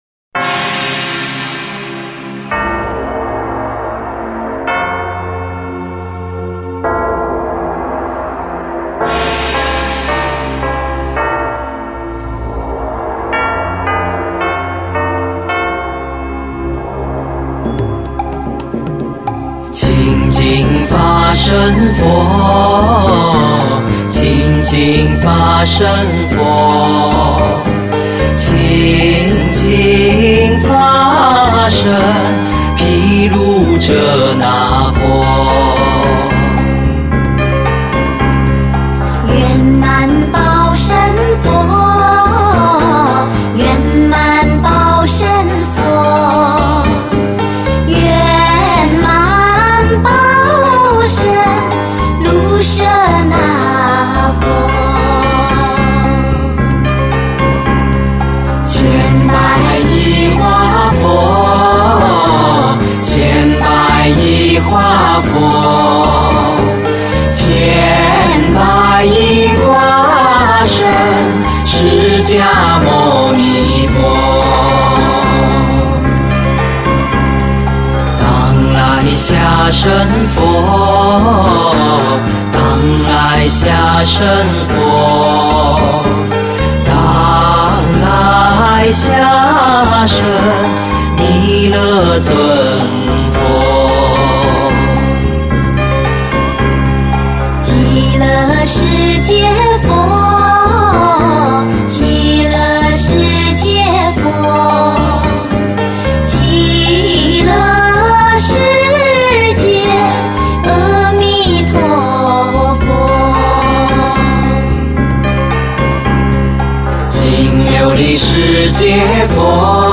[27/2/2010]优美抒情，悠扬动听的男女合唱 - 佛教歌曲：清净法身佛